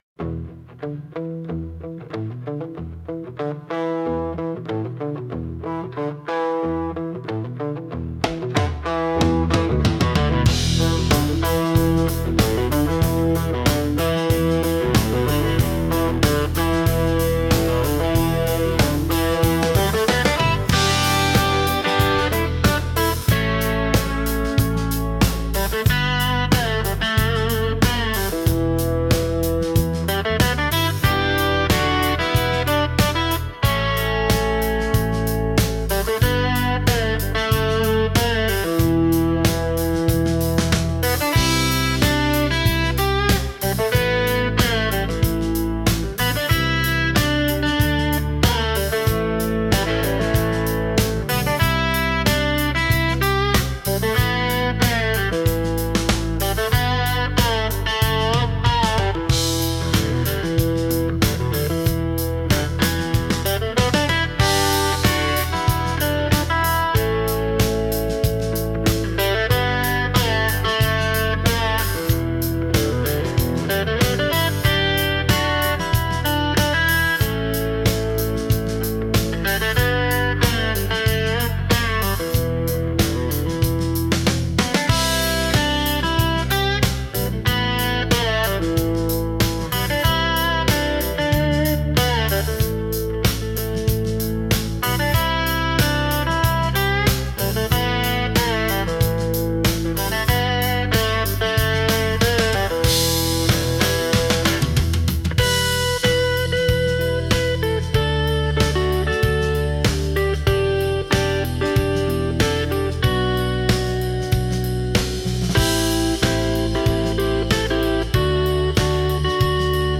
Instrumental - Crows Beneath the Wires - Real Liberty Media .